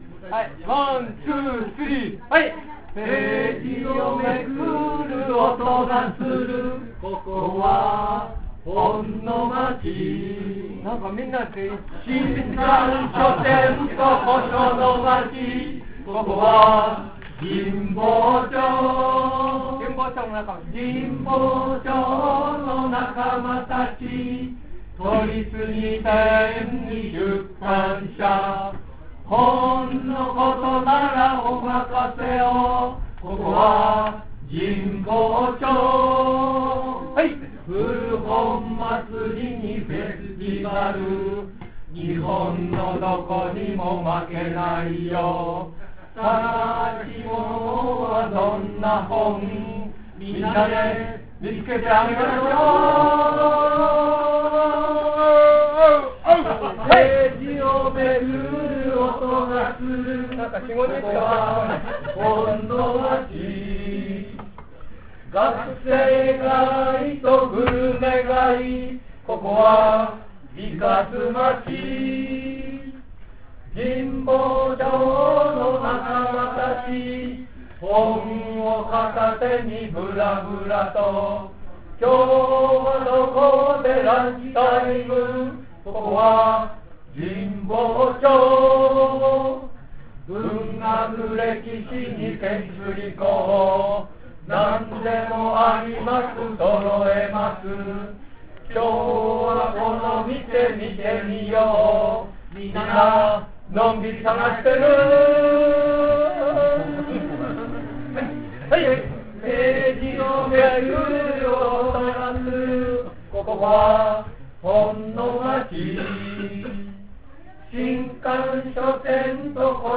「本の街　神保町」のイベント「神保町ブックフェステイバル」で歌われた。
誰でも歌えるなつかしいフォーク調のメロティーです。
オジ・オバ合唱隊（歴史書懇話会話会芸能部）